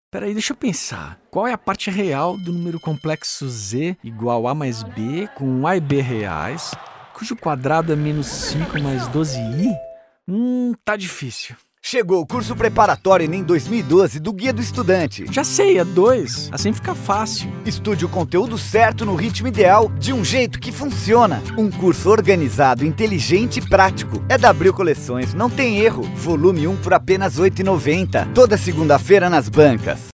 Dinap_Enem_Spot_2012.mp3